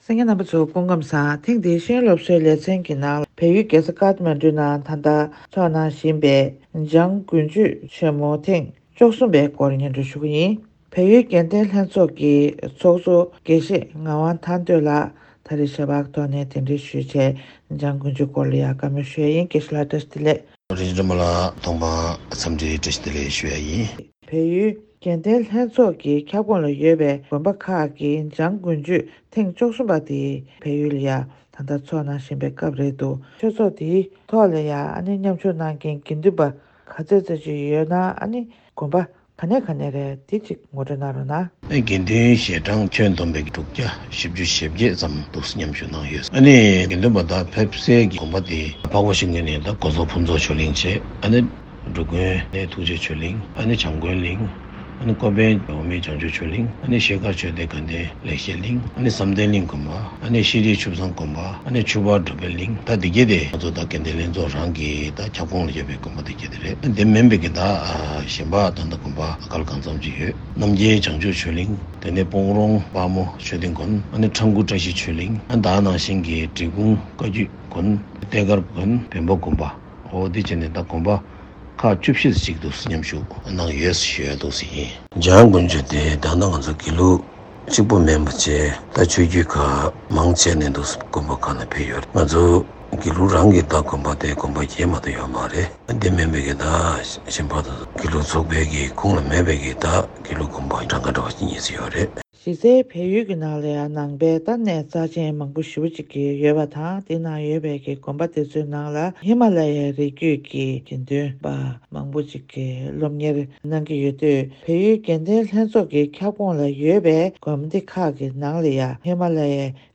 བལ་ཡུལ་འཕགས་ཤིང་ཉེས་འགྲམ་སྒོ་སོག་ཕུན་ཚོགས་ཆོས་གླིང་དགོན་དུ་འཇང་དགུན་ཆོས་སྐབས་༡༣ པ་ཚོགས་གནང་བཞིན་ཡོད་པ་ལྟར་འཇང་དགུན་ཆོས་ཀྱི་ལོ་རྒྱུས་སྐོར་བཀའ་འདྲི་ཞུས་པ།